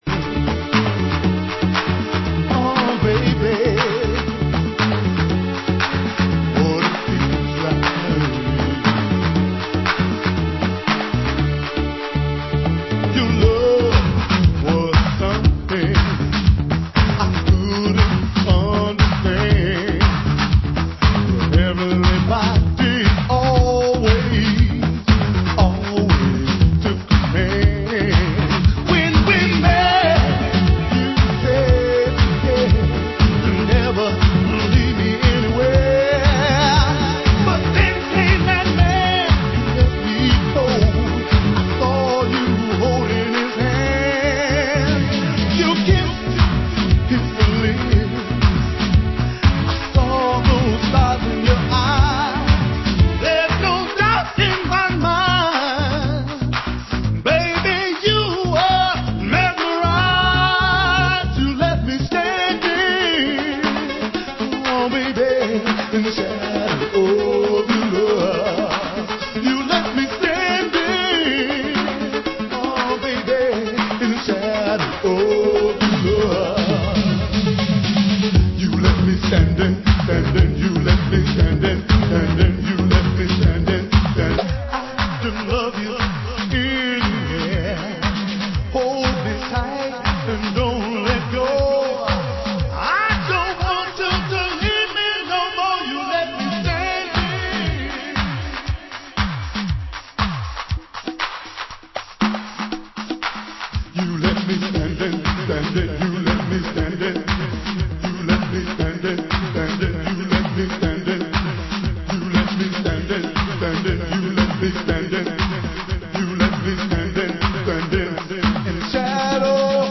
Genre: US House
House Mix, Acappella/Beats Mix, Fierce Mix, Original Mix